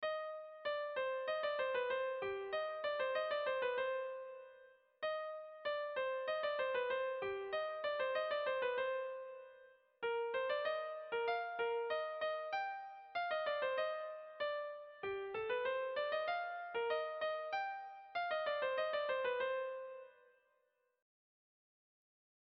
Zortziko txikia (hg) / Lau puntuko txikia (ip)
A1A2B1B2